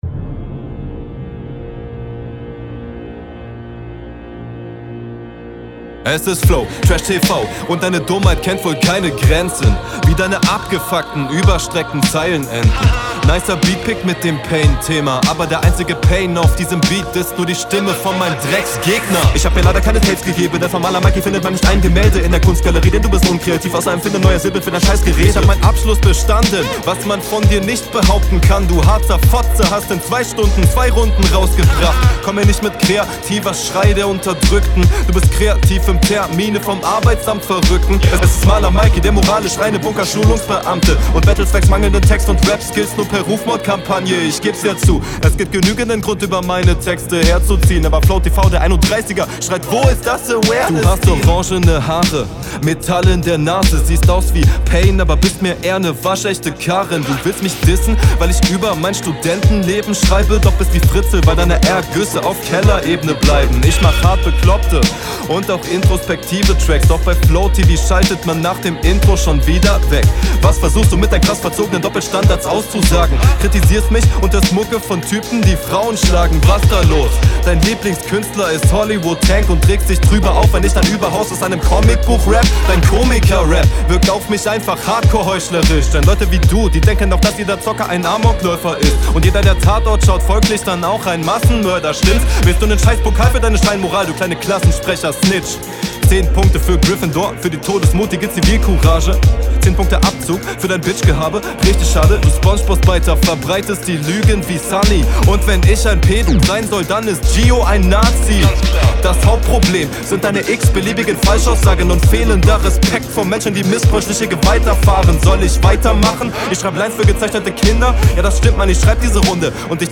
Flow wieder sehr nice.
Flowlich super souverän, find ich richtig geil.